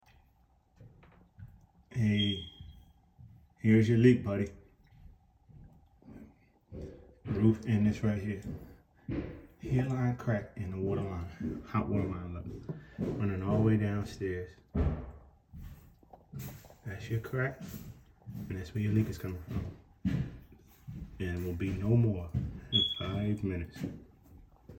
Customer complained about dripping water sound effects free download
Customer complained about dripping water sound in his ceiling after moving into his new home.